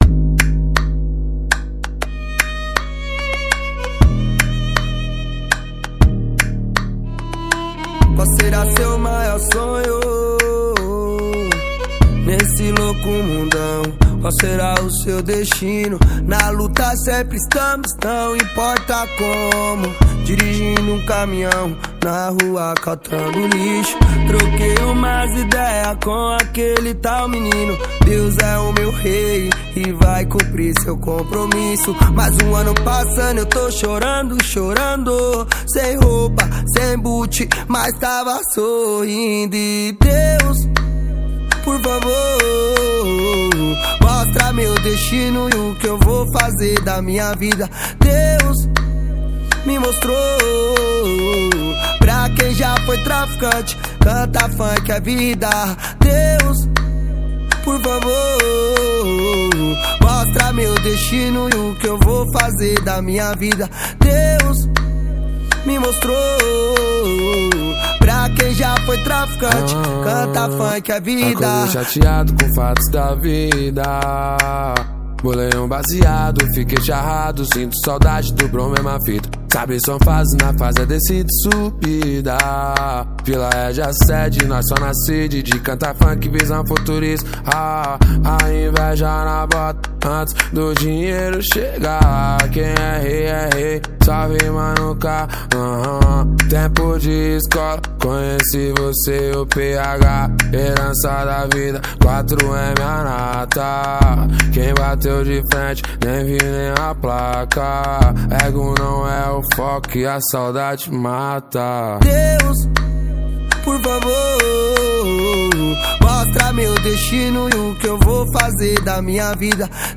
2024-06-11 02:02:44 Gênero: MPB Views